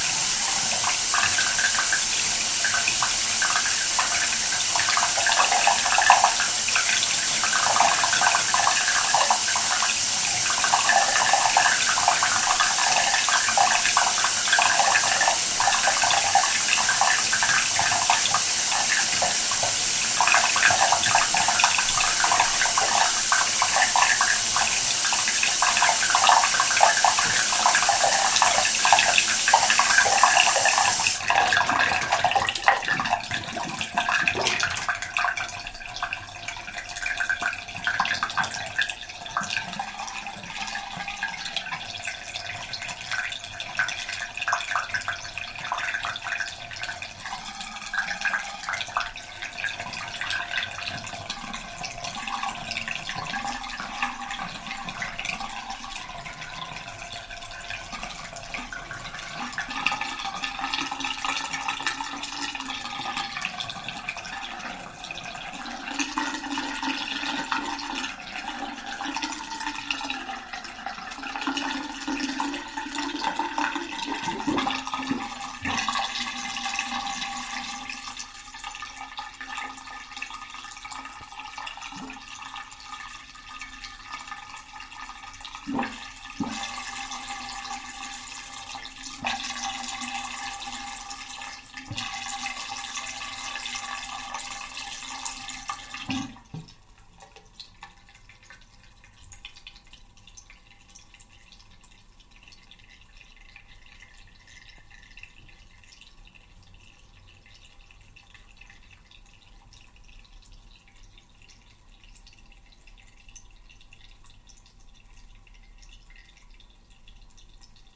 浴缸排水
描述：水从浴缸中排出，麦克风靠近排水口。有很多潺潺的声音，最后当浴缸里的水排空时，会有很好的低音。
标签： 环境 - 声音的研究 浴缸 排水 汩汩 浴缸
声道立体声